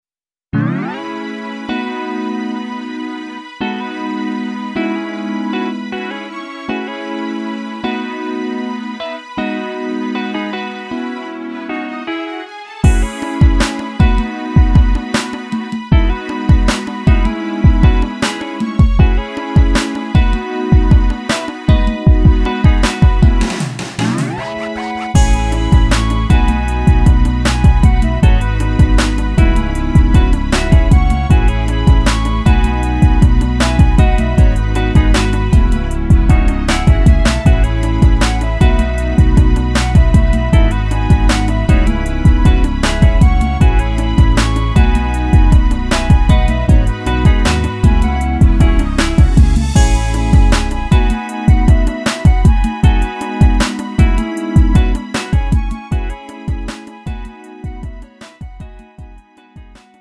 음정 원키 3:59
장르 구분 Lite MR